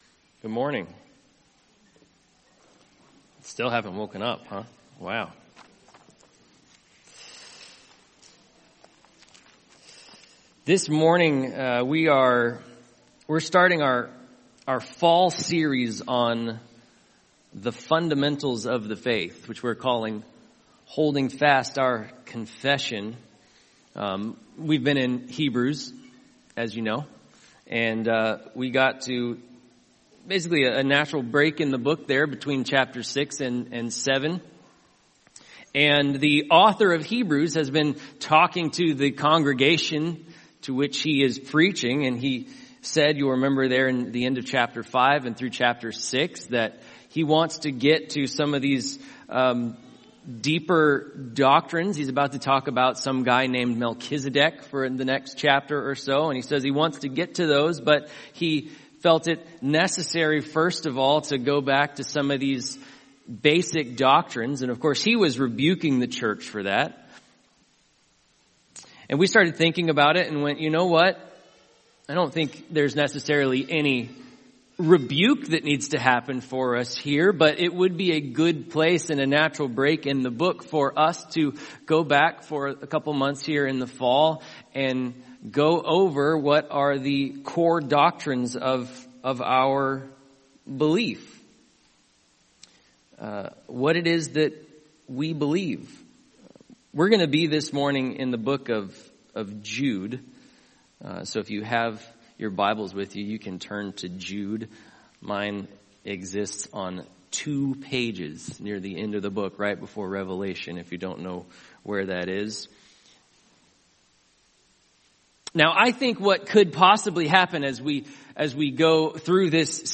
Passage: Jude Service: Sunday Morning « Save Us